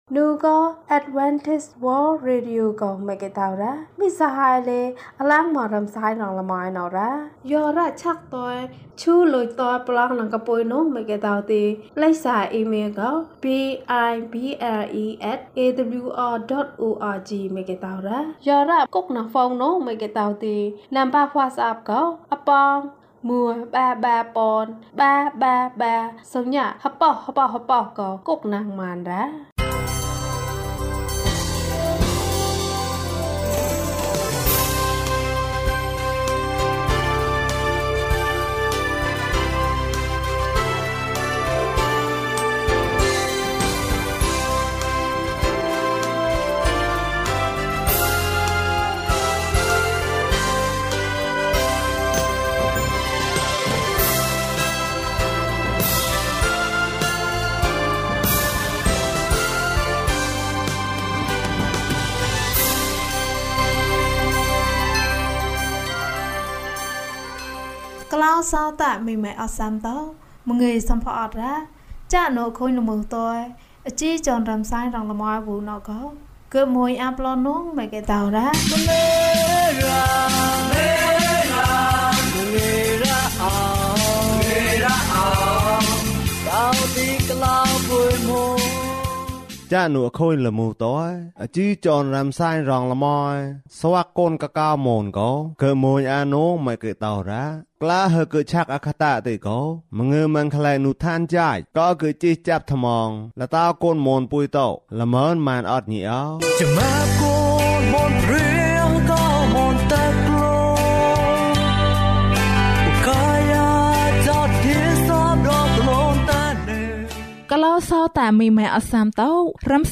ခရစ်တော်ထံသို့ ခြေလှမ်း။၃၄ ကျန်းမာခြင်းအကြောင်းအရာ။ ဓမ္မသီချင်း။ တရားဒေသနာ။